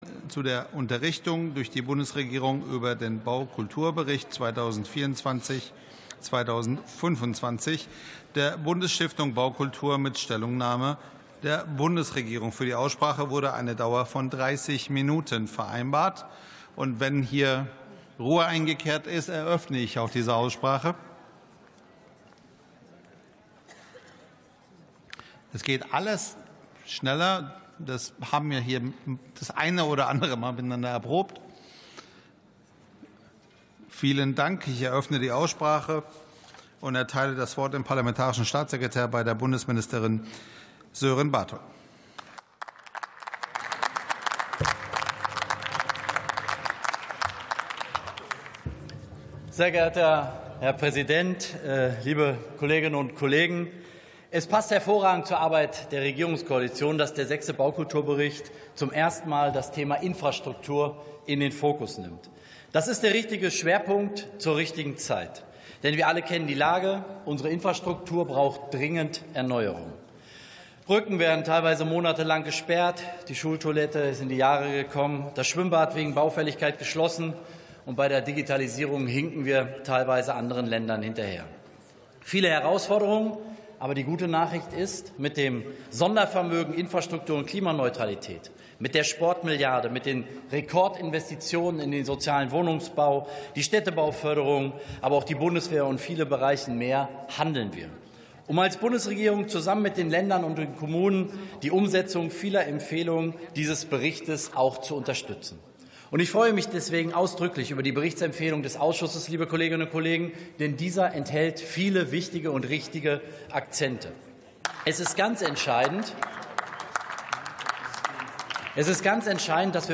62. Sitzung vom 05.03.2026. TOP 15: Baukulturbericht 2024/25 ~ Plenarsitzungen - Audio Podcasts Podcast